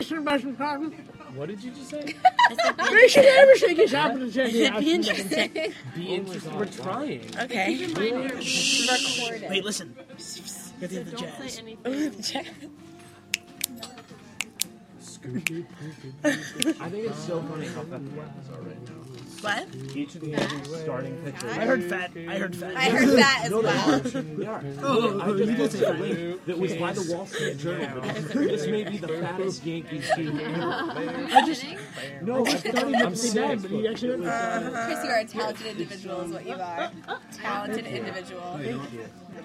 Loud chatter – Hofstra Drama 20 – Sound for the Theatre
Field Recording #1
Sounds in Clip : Various levels of talking/chatter from various distances, phone dropping on table, bags being dropped on chair, hollow bottle being dropped on table, clanking and moving of chairs
Location: Al Hirschfeld Theatre during intermission at a performance of Moulin Rouge